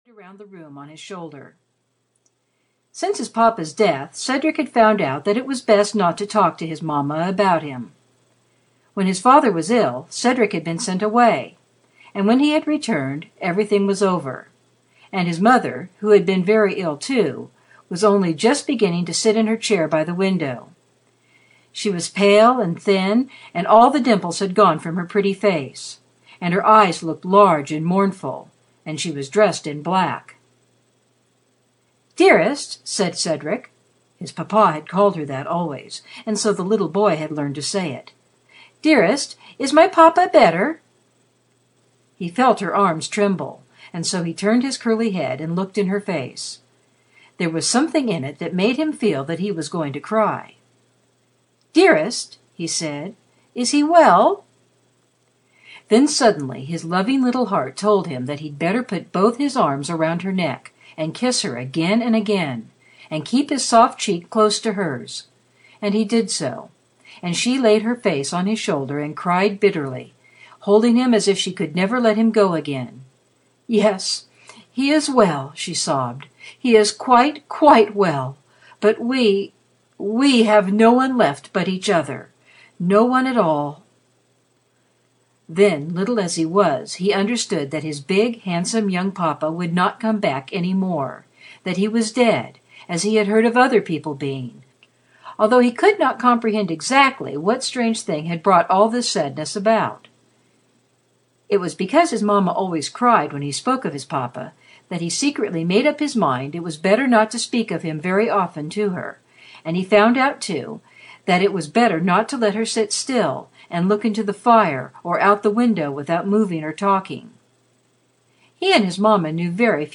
Little Lord Fauntleroy (EN) audiokniha
Ukázka z knihy